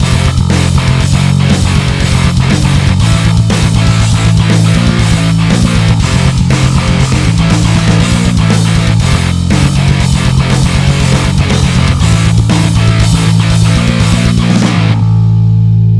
-4,8 LUFS... wenn der player nicht funzt
Ja, also zwei Dinge: ohne vorher kein Vergleich und wenn ich das Schlagzeug so leise mische, dann komme ich auch auf diesen Wert mit jedem Clipper.